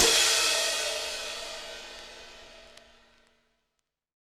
Crashes & Cymbals
CymST_70s_4_MPC60V.wav